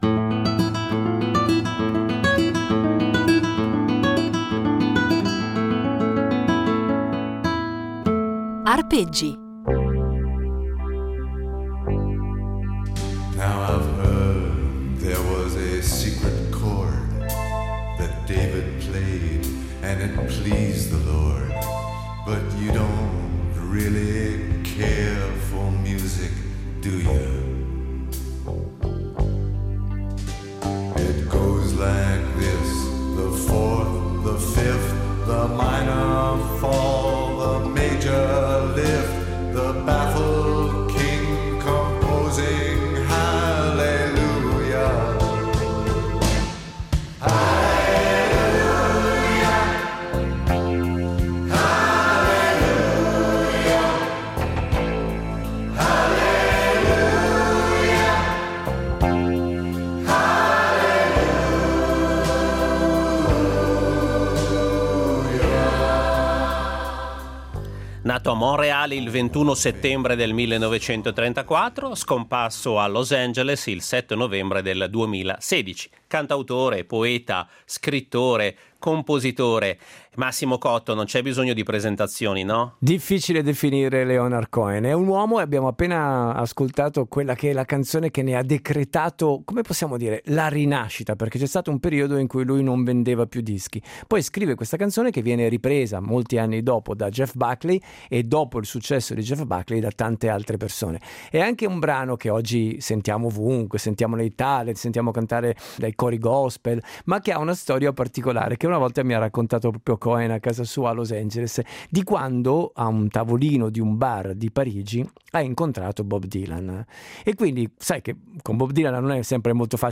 Il giornalista, disc jockey e scrittore piemontese Massimo Cotto ha avuto la fortuna, durante la sua lunga carriera, di incontrare e intervistare un gran numero di nomi illustri della musica italiana e internazionale.